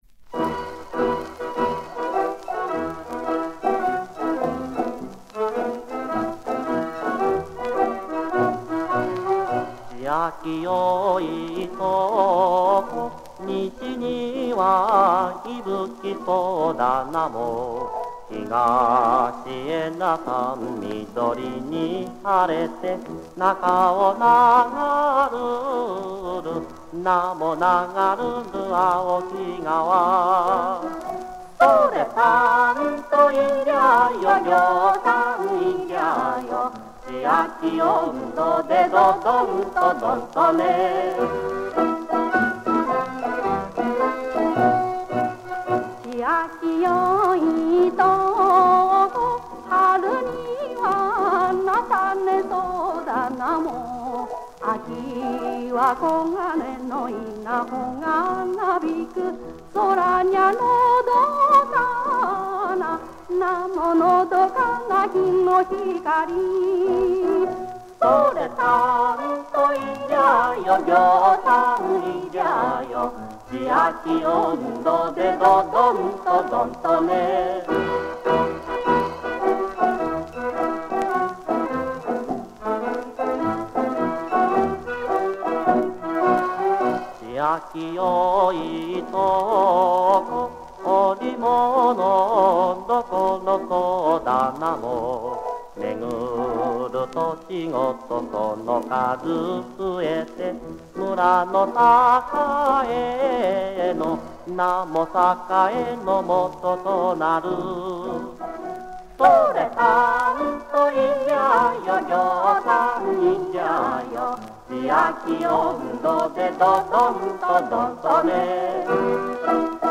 先日の盆踊り大会で掛けられていたものと少し異なり、こちらも懐かしい響きでした。